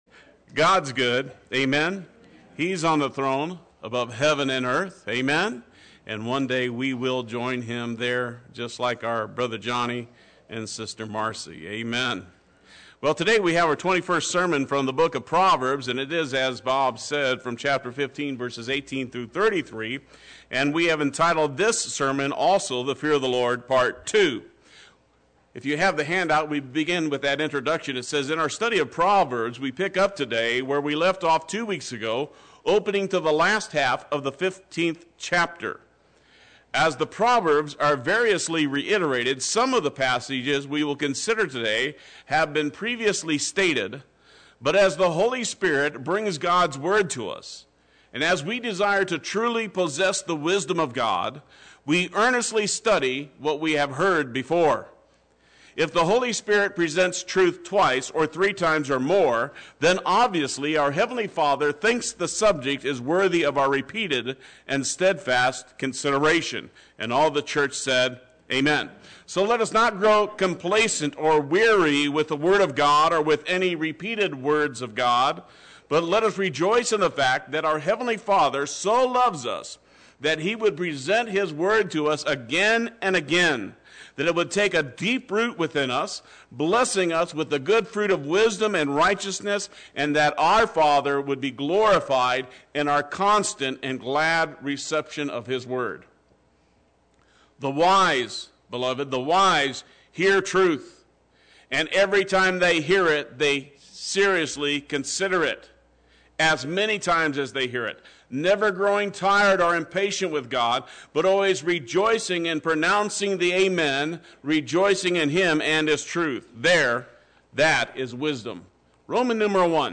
Play Sermon Get HCF Teaching Automatically.
Part II Sunday Worship